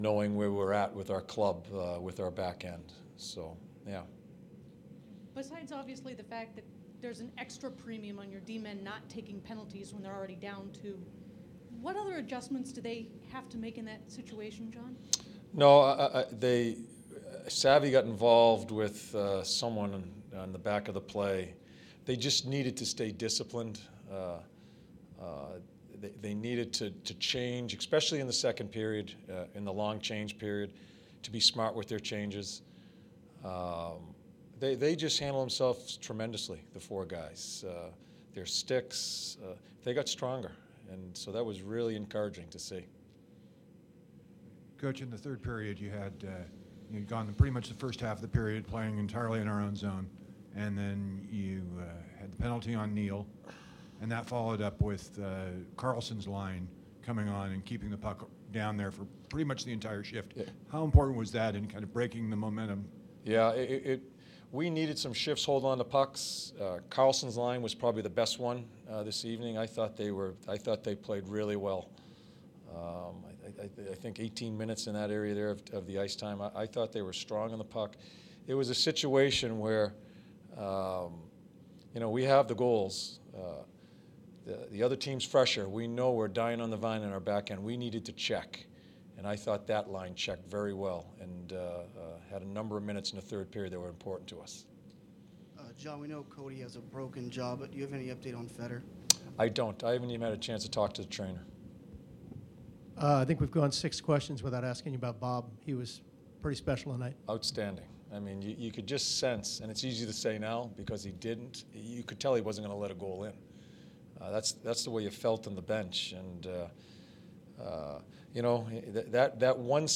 John Tortorella Post-Game in the Jackets 4-0 win against the Nashville Predators 11-20-15